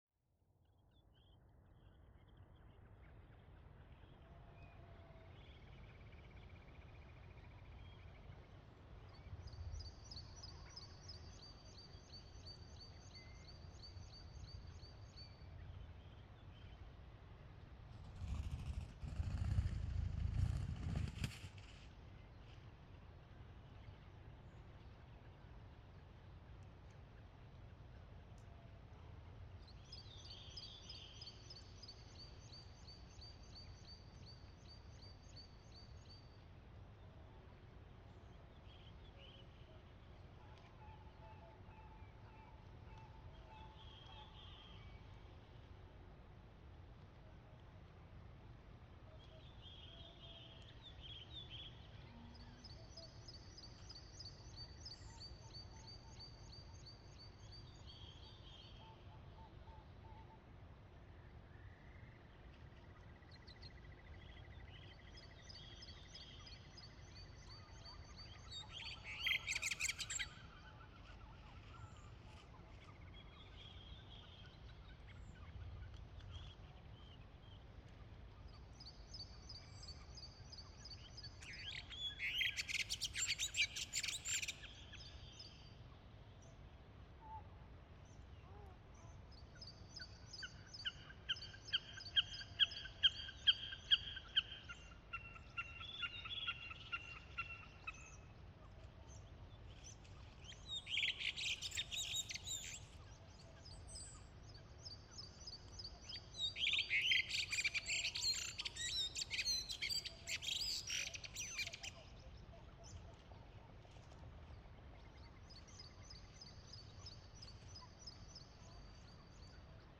The grass was tall so the microphones were almost hidden in the grass. Lots of birds were in this grass searching for food and some of them came close to the microphones. This is a typical soundscape of silence in a countryside which is not disturbed by traffic or by other engine noise.
Front of you is a wide open wetland field.